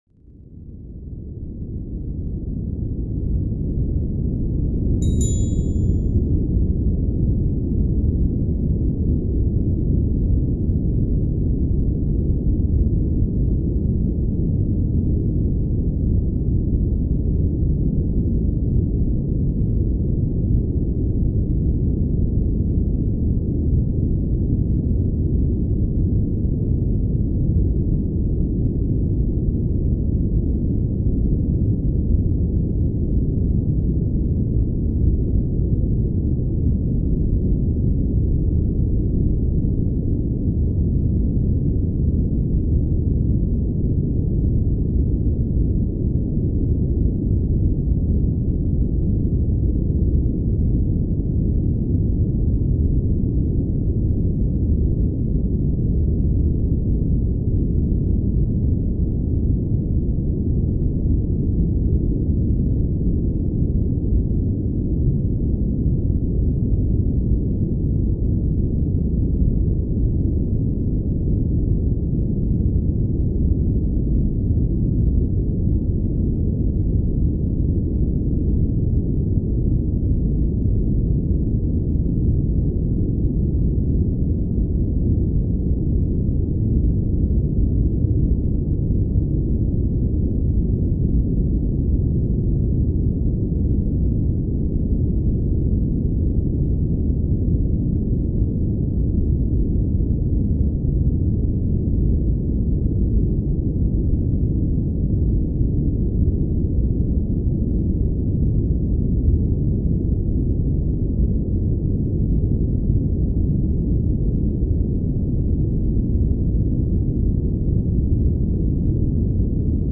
【勉強集中】ブラウンノイズ×ポモドーロタイマー 1時間連続再生